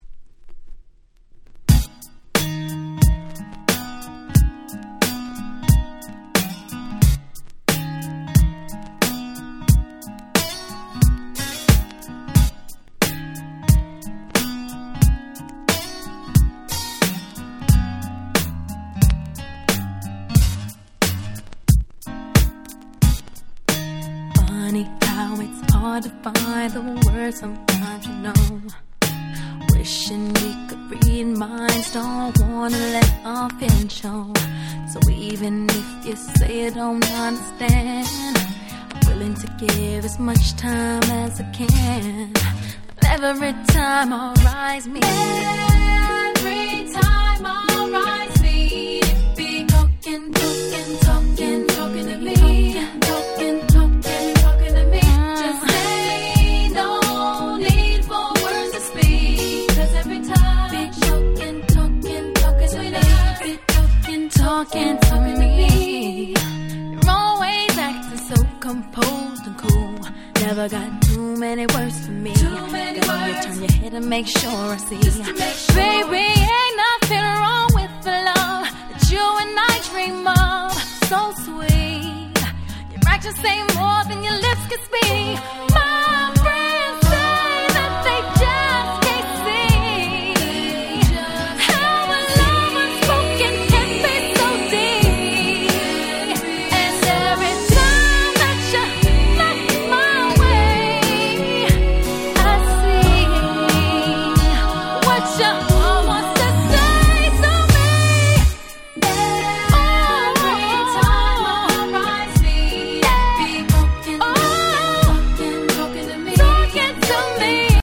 02' Smash Hit R&B !!